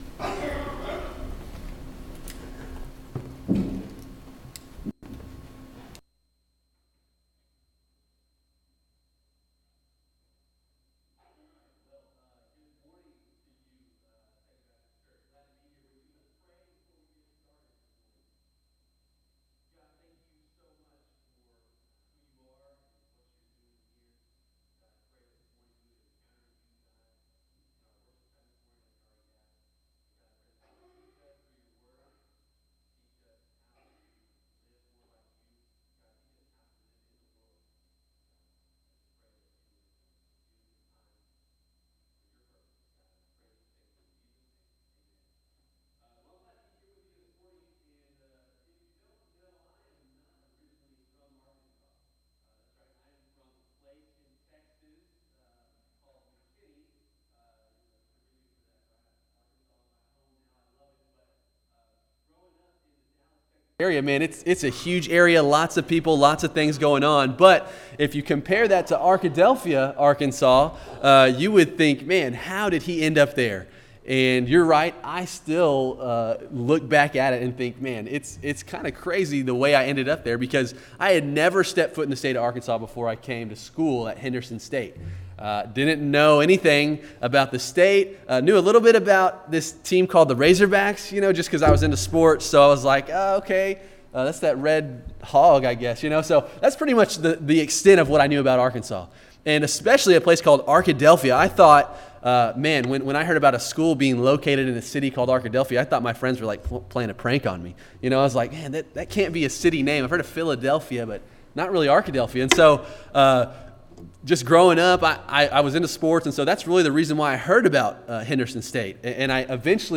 Sunday Sermon June 5, 2016